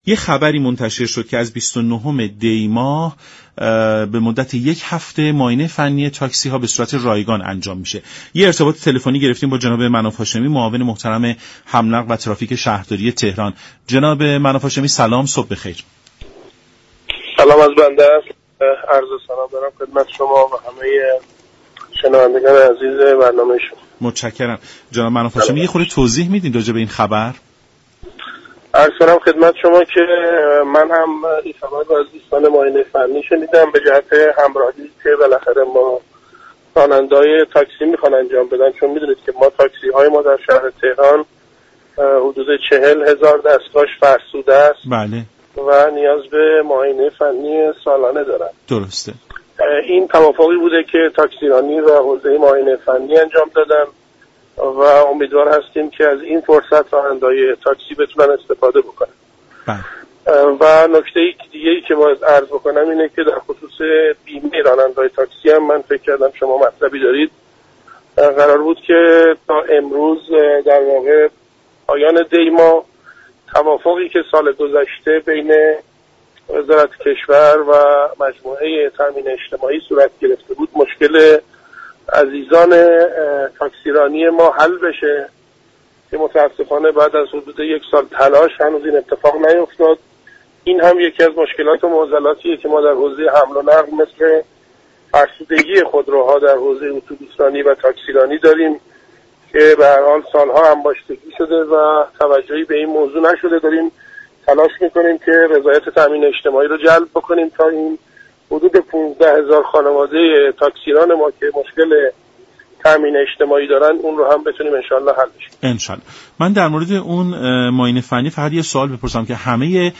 به گزارش شبكه رادیویی ایران، مناف هاشمی معاون حمل و نقل و ترافیك شهرداری تهران در برنامه سلام صبح بخیر رادیو ایران از معاینه فنی رایگان یك هفته ای تاكسی های تهران خبر گفت: از آنجا كه 40 هزار دستگاه تاكسی در تهران فرسوده هستند و نیاز به معاینه فنی سالانه دارند، طرح اخیر سازمان تاكسیرانی تهران كه با همكاری ستاد معاینه فنی انجام شده، فرصت مناسبی برای تاكسیرانان است.